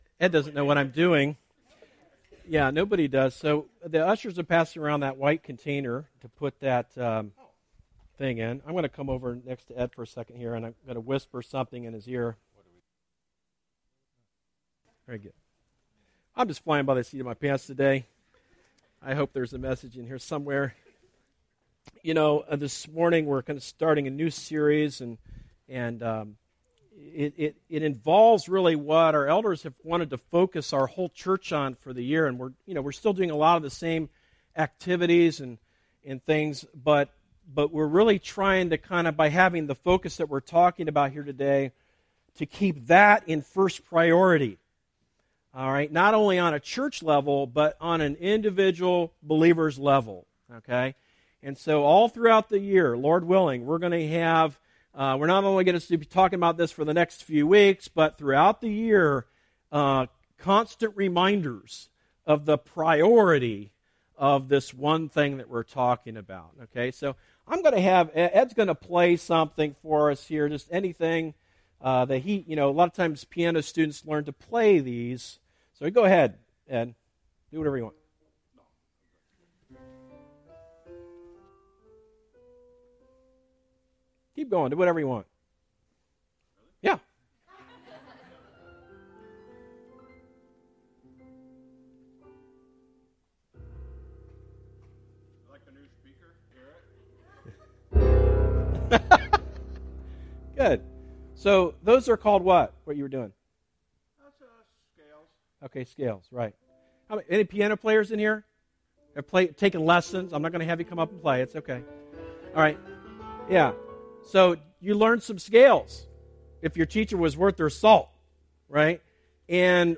Sermons that are not part of a series